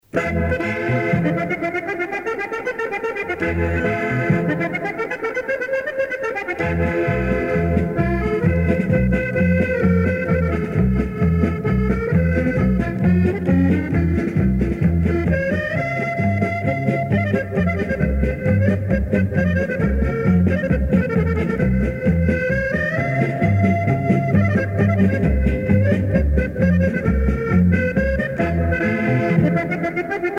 danse : paso musette
Pièce musicale éditée